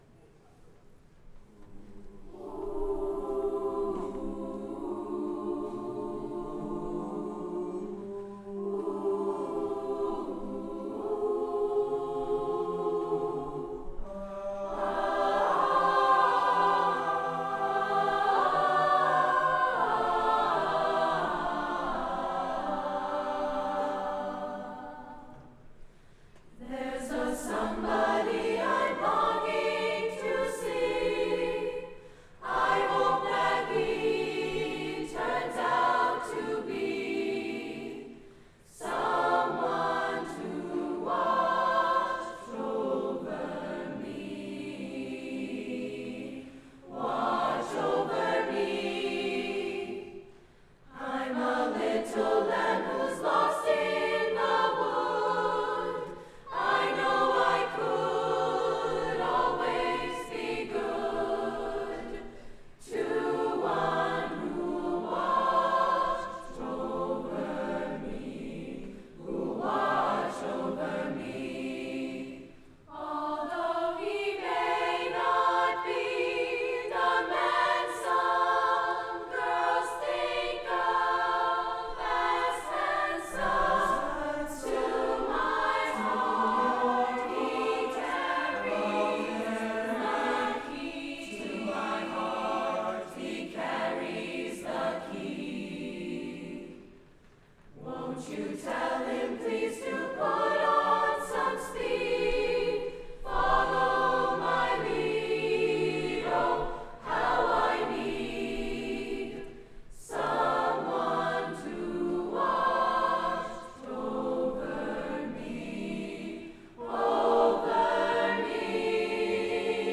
Brookline High School Spring Music Festival
A cappella Choir